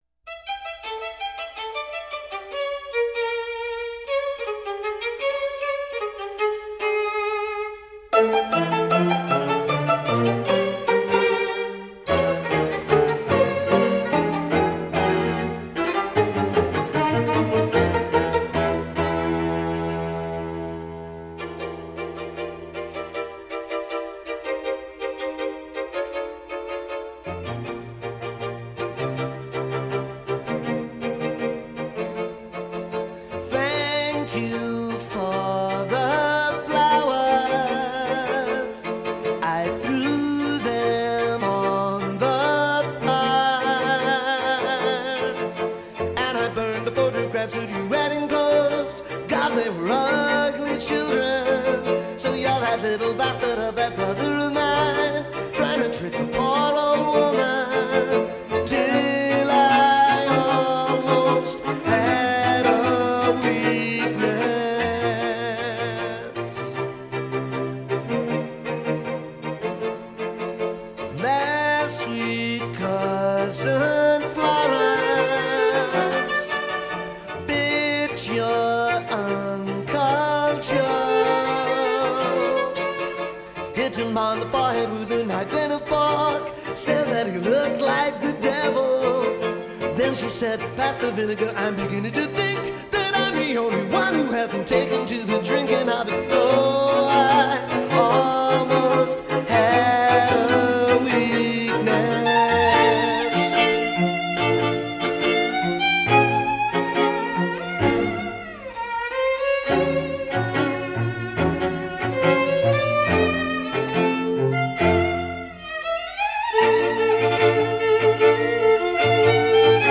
cuarteto de cuerda